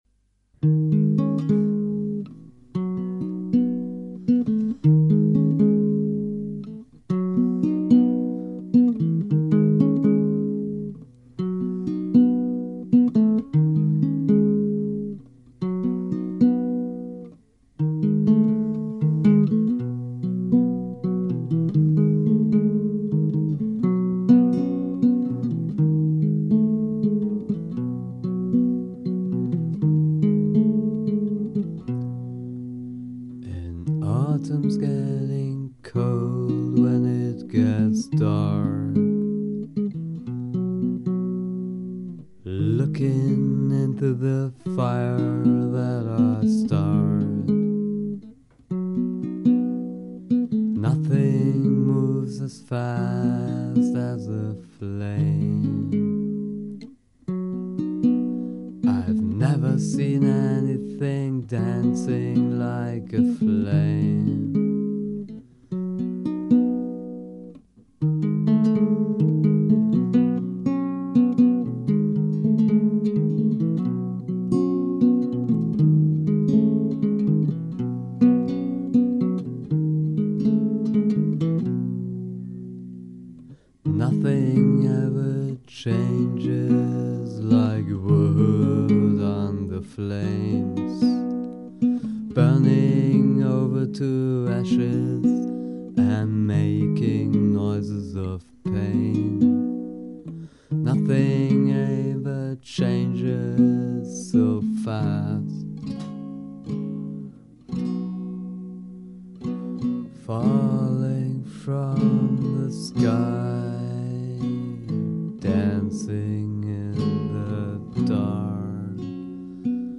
Gitarren Rock
voc, git, banjo, whistle, special noise effects
bass
drums